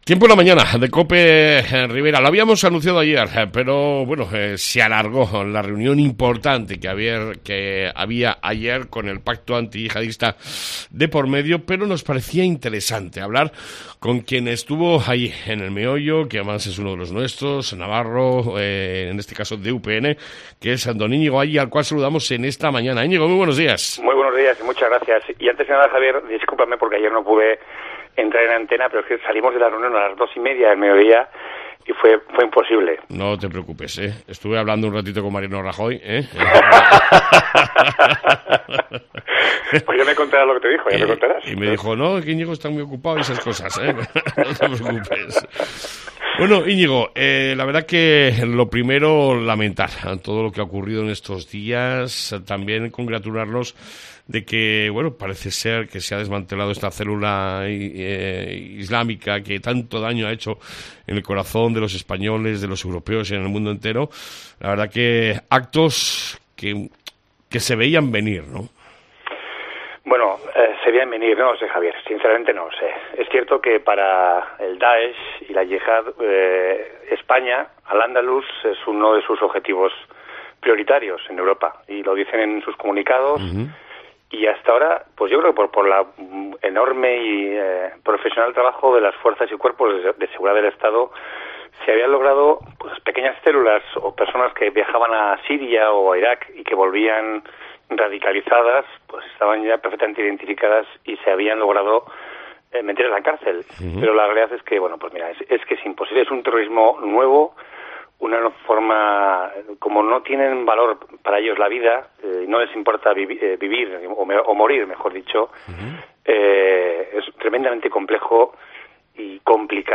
Entrevista con Iñigo Alli